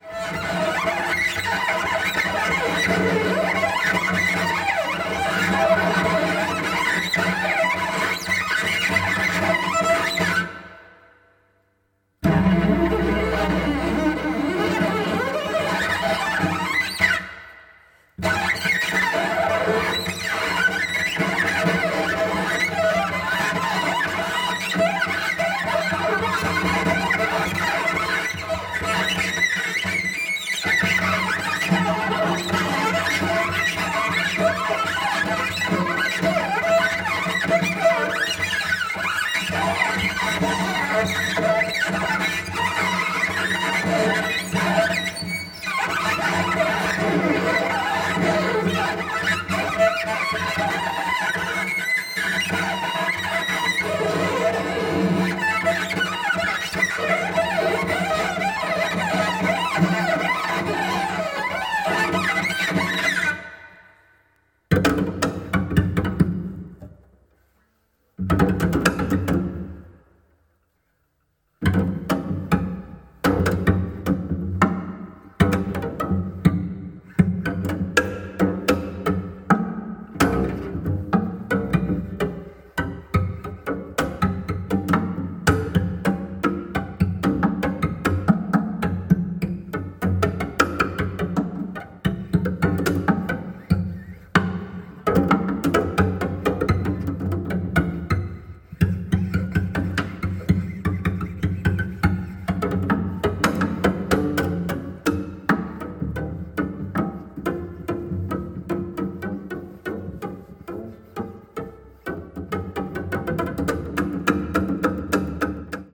チェロの音色の可能性を独自の研究でコントロール、完全に物音化した抽象反復やら鬼気迫る摩擦音を様々な角度より放射。